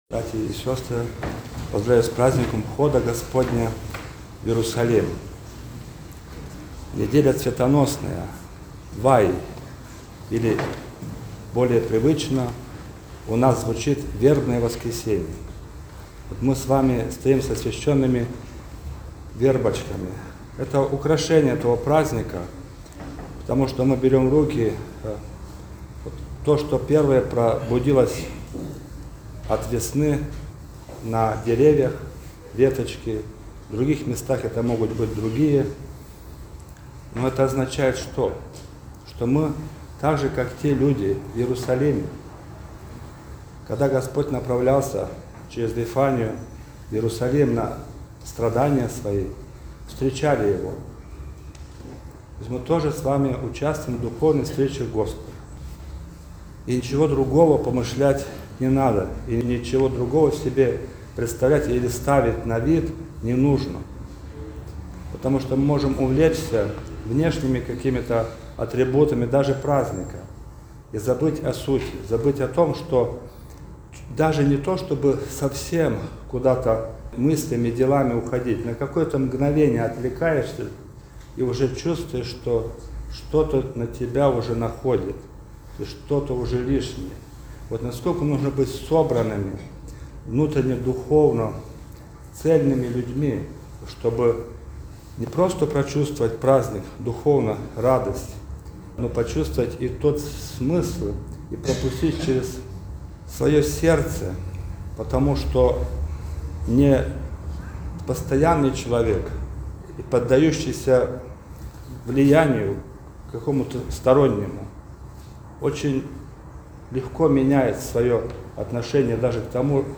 Всенощное бдение
Вербное_воскресенье_Всенощное_бдение.mp3